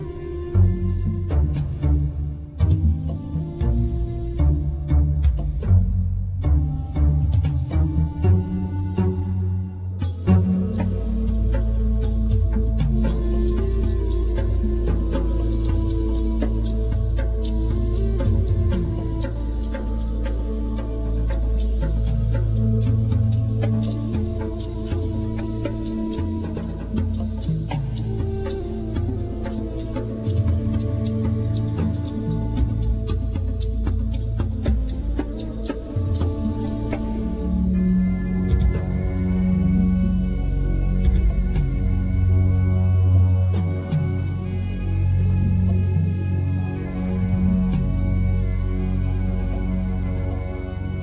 Ud, Bendir, Nay, Turkish Qanun, Tajira, Darbuka,Keyboard